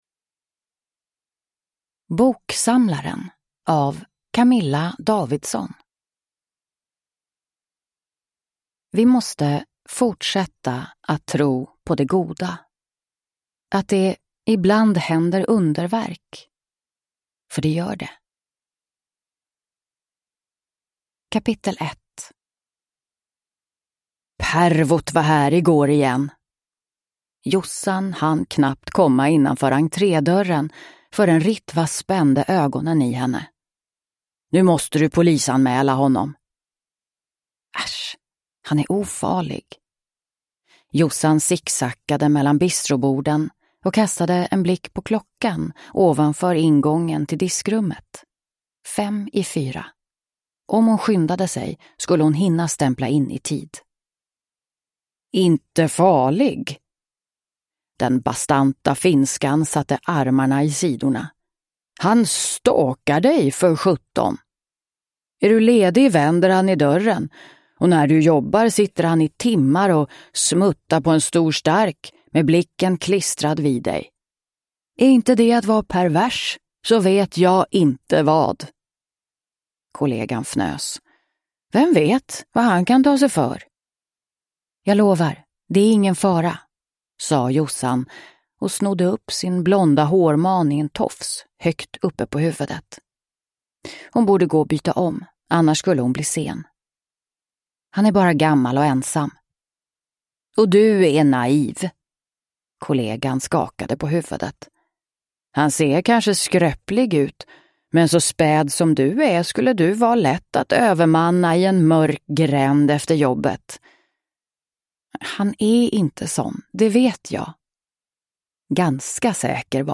Boksamlaren (ljudbok) av Camilla Davidsson